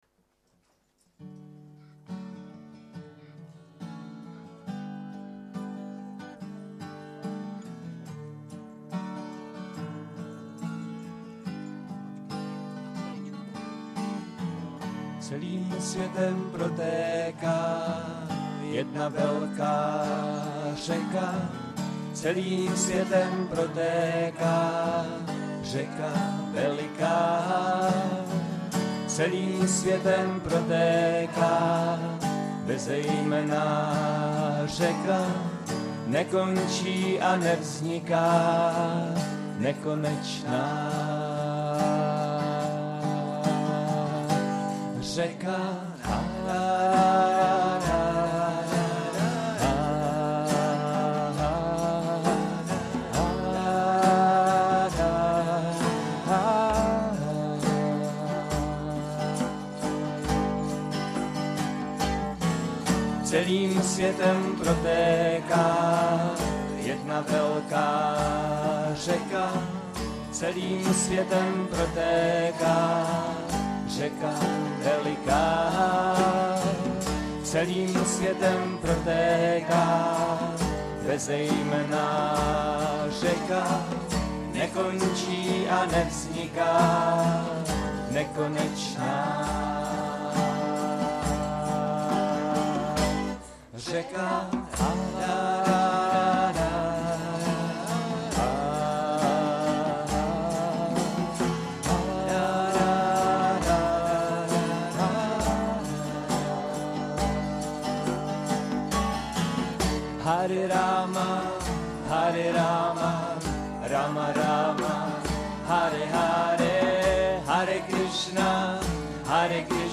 Nahrávka ze zpívání (live record)